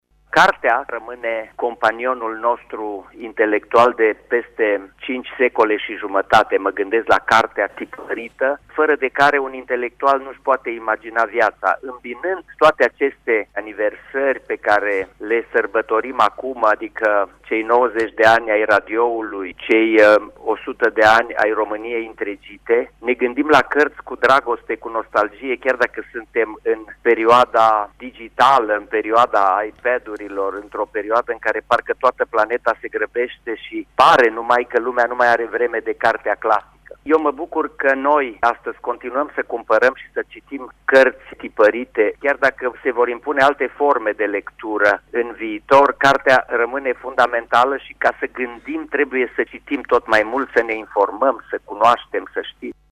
Preşedinte de onoare al acestei ediţii este şeful Academiei Române, Ioan-Aurel Pop. Acesta a vorbit, la Radio România Actualităţi, despre importanţa cărţii tipărite, chiar şi în epoca digitală: